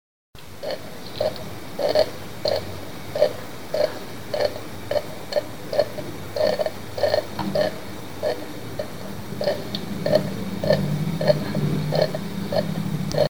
Rayando una zanahoria
Grabación sonora de lo que parece el sonido que produce una zanahoria al ser frotada contra un rayador para rayarse o laminarse.
Sonidos: Acciones humanas
Sonidos: Hostelería